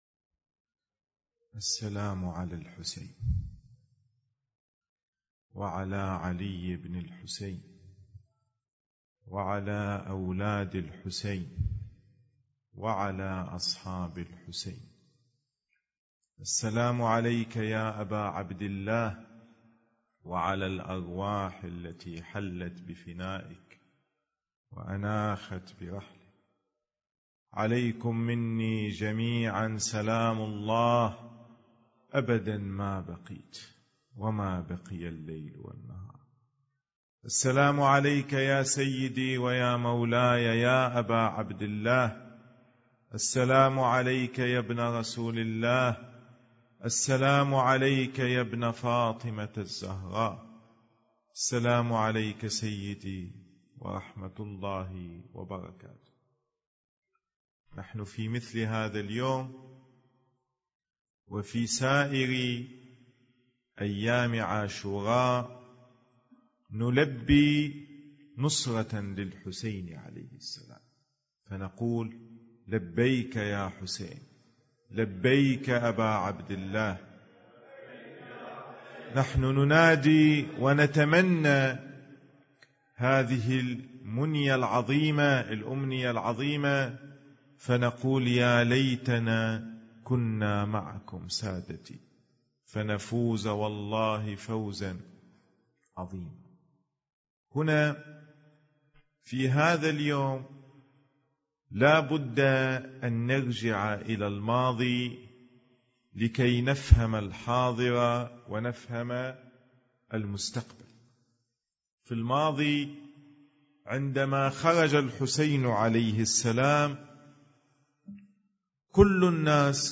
كلمة يوم العاشر من المحرم 1443 هـ - شبكة رافـد للتنمية الثقافية